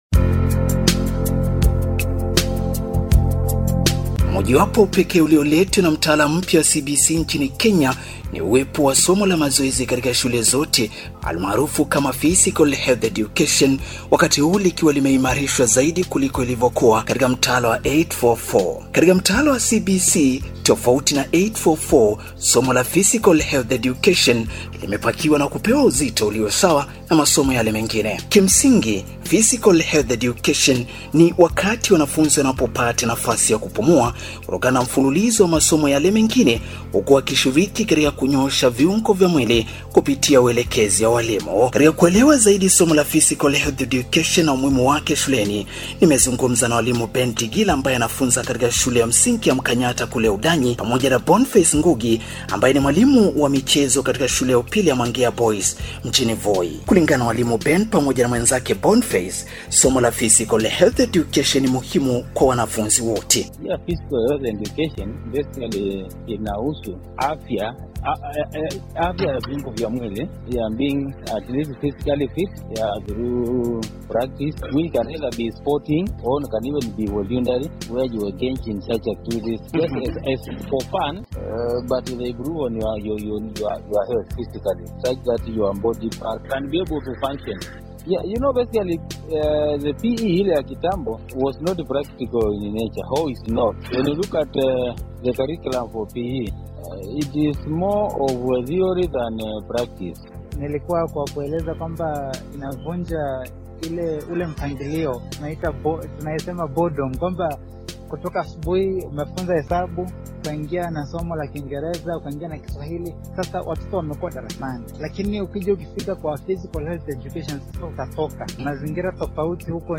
Interview on the Introduction of P.E | Sikika Platform